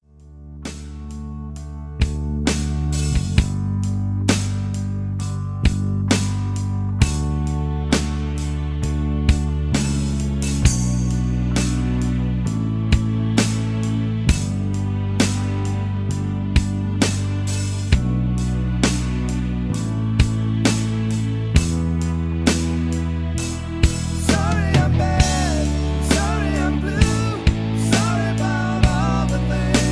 karaoke , mp3 backing tracks